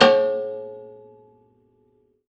53w-pno01-C2.wav